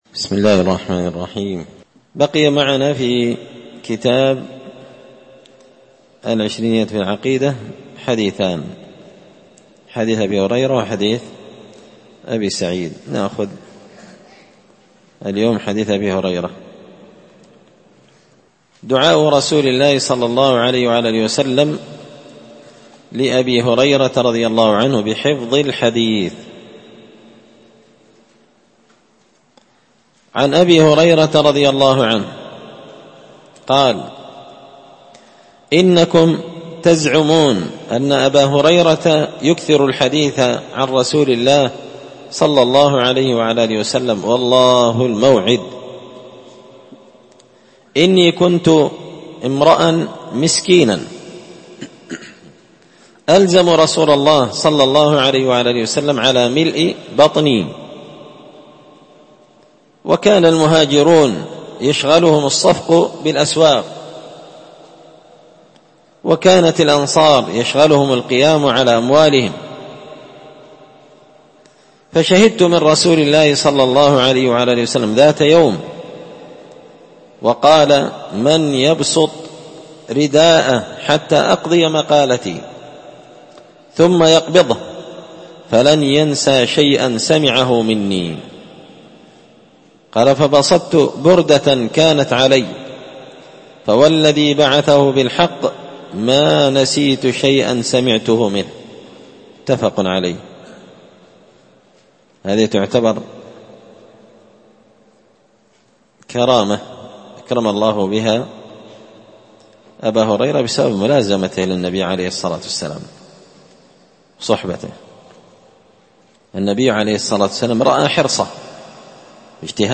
الدرس 214
مسجد الفرقان قشن_المهرة_اليمن